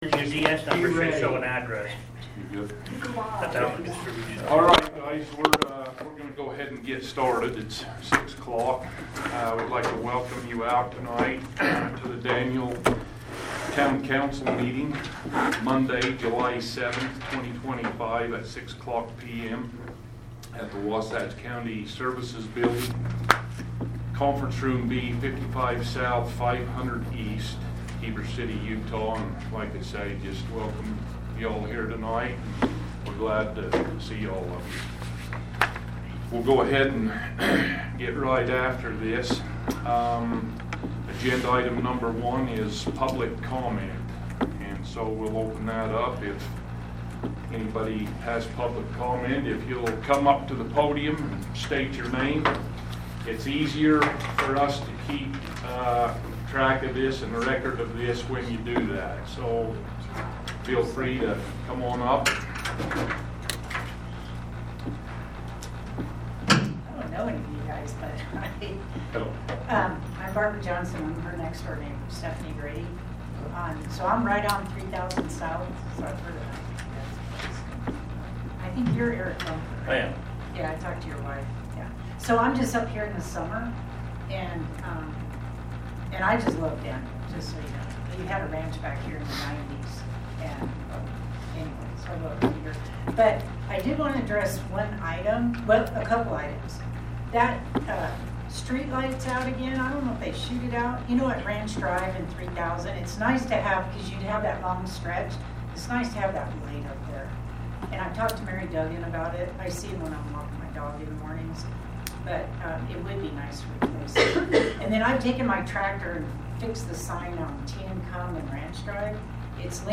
July 7, 2025 Town Council Meeting AUDIO